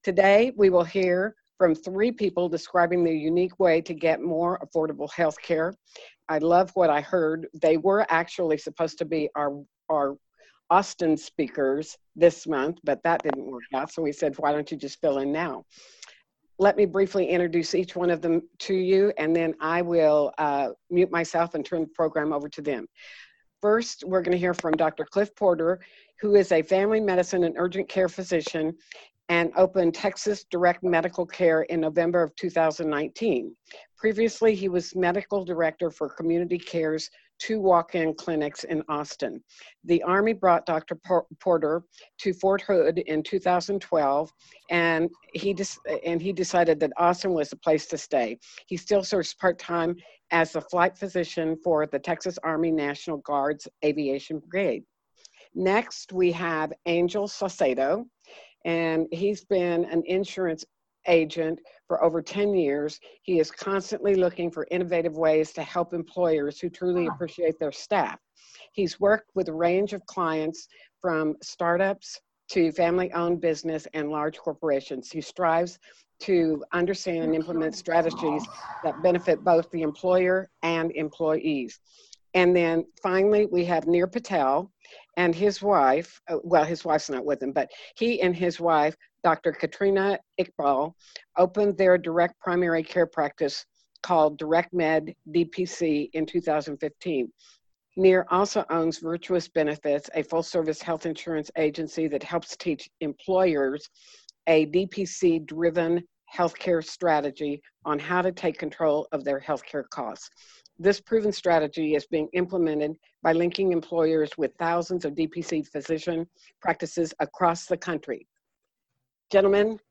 Healthcare Options Online Conference